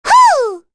Rehartna-Vox_Attack2.wav